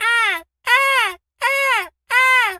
seagul_squawk_seq_06.wav